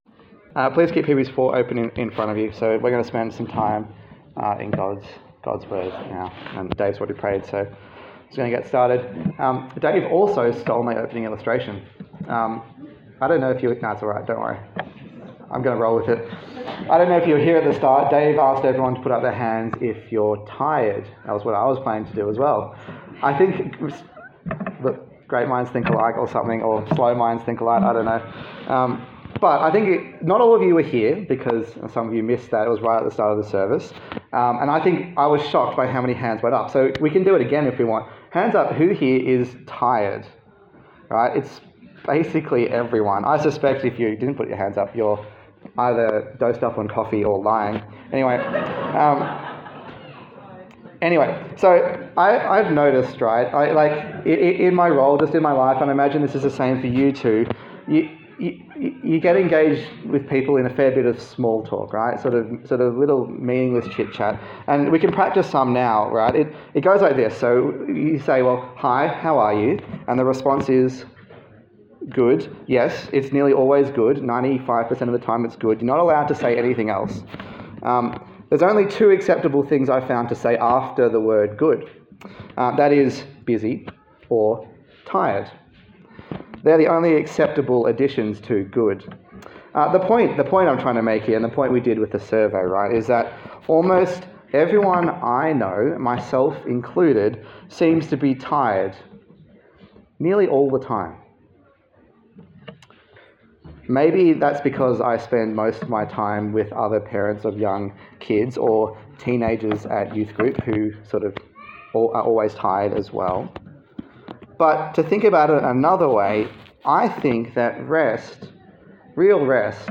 Hebrews Passage: Hebrews 4:1-13 Service Type: Sunday Service